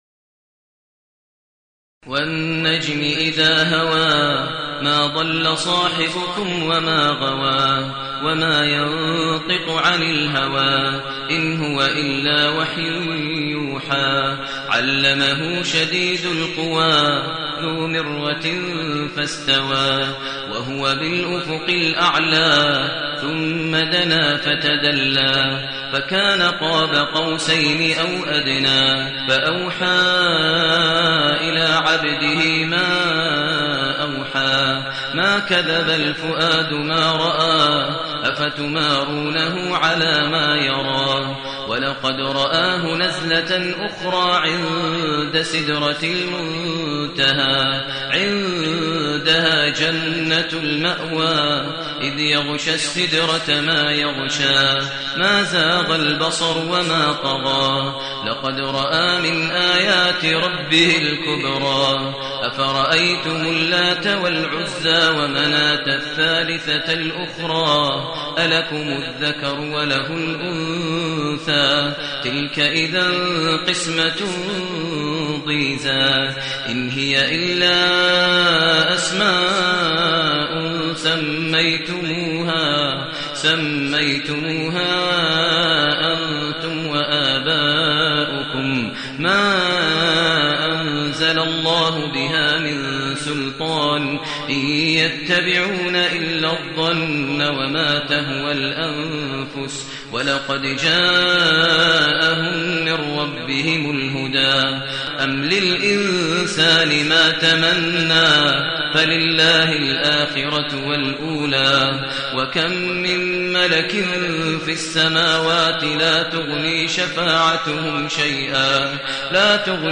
المكان: المسجد النبوي الشيخ: فضيلة الشيخ ماهر المعيقلي فضيلة الشيخ ماهر المعيقلي النجم